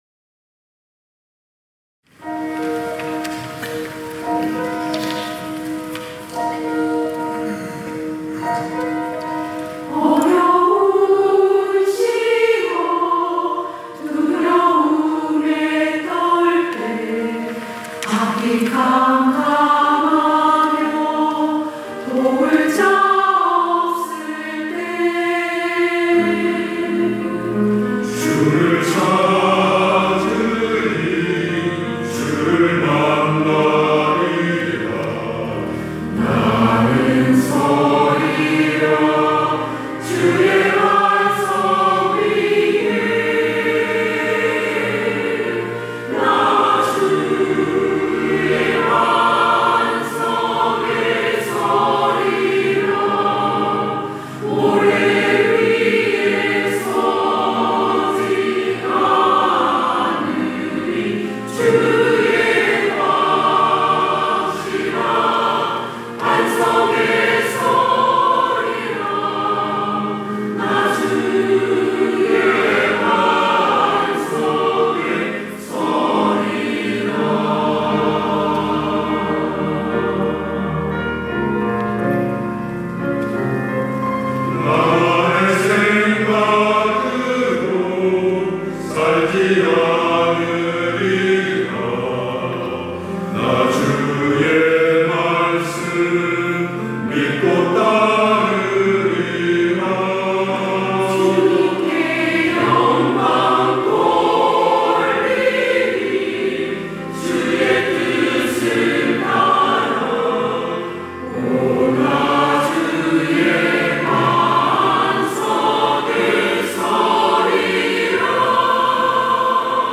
시온(주일1부) - 반석 위에 서리라
찬양대